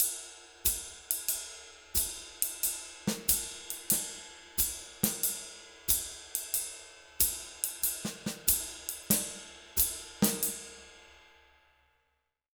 92SWING 02-R.wav